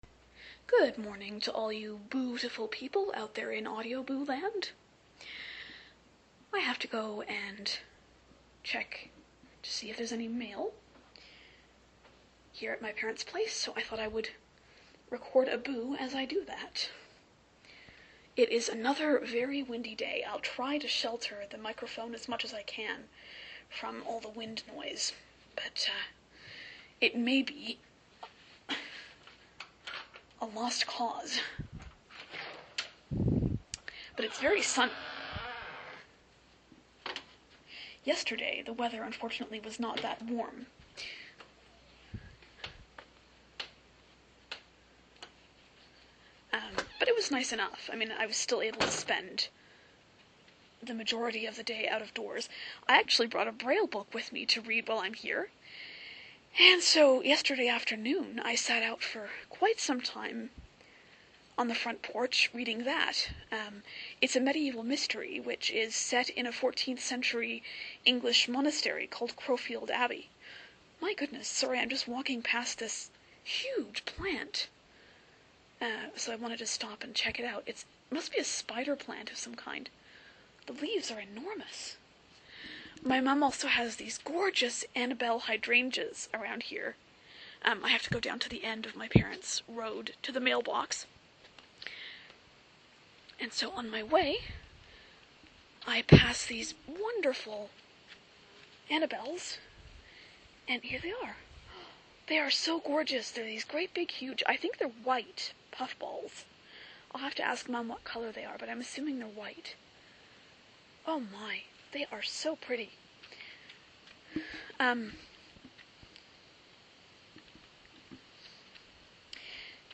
Join me as I take a walk down my parents' country road to check for mail.